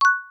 BrickHit.wav